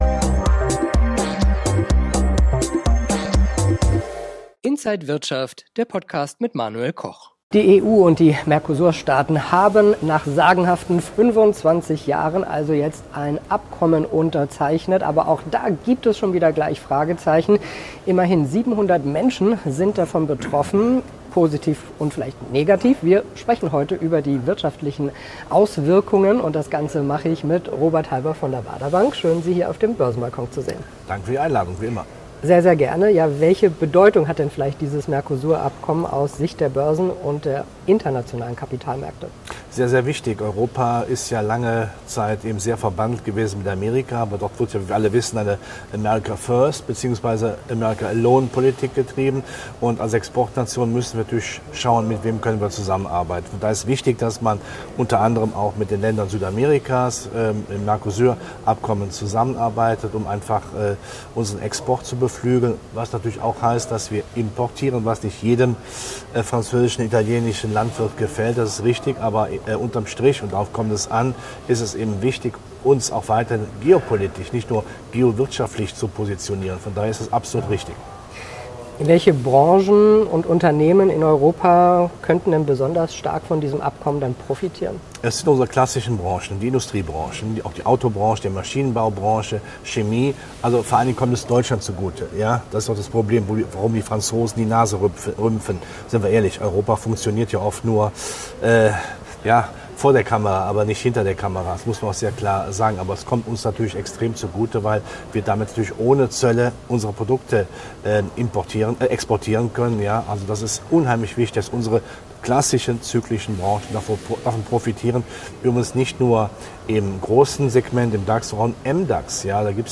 Alle Details im Interview